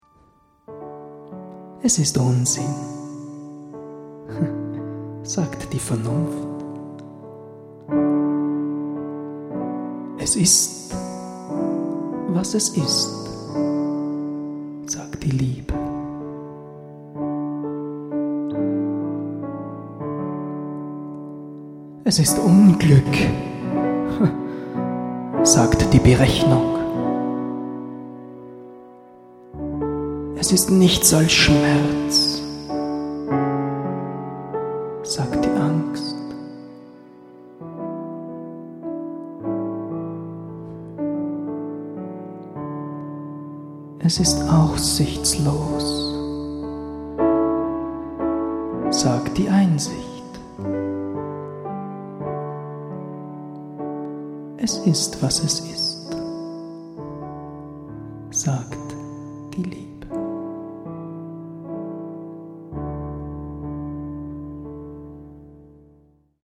Eine musikalische Dichterlesung